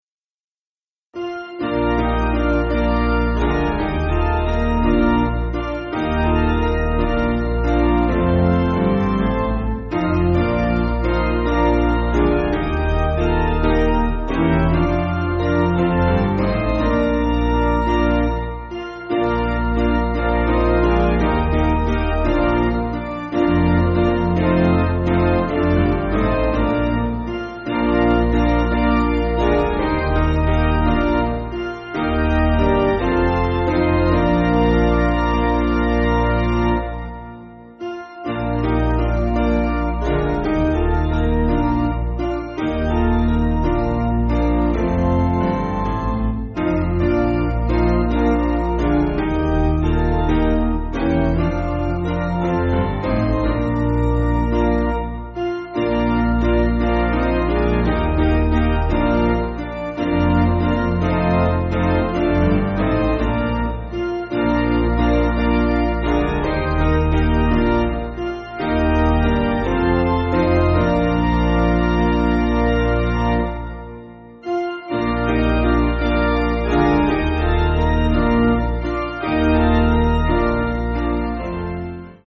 Basic Piano & Organ
(CM)   3/Bb